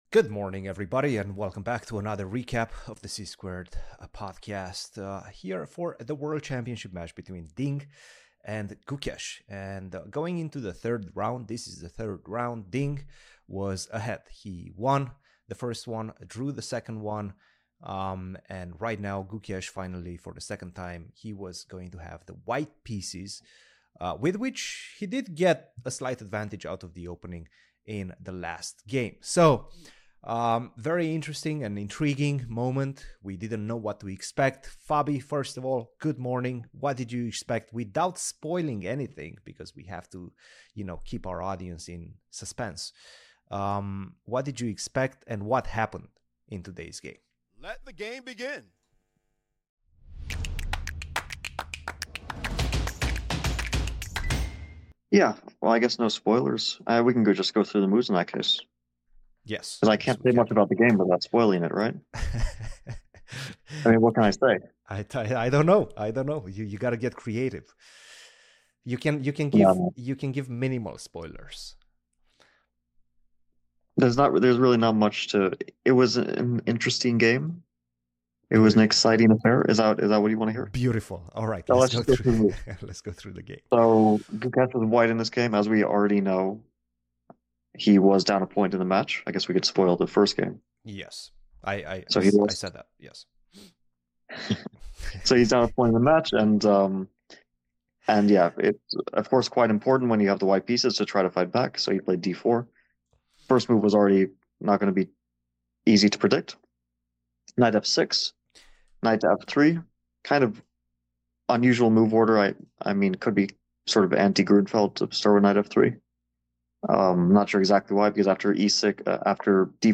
The C-Squared Podcast is an in depth weekly discussion about the chess world with your hosts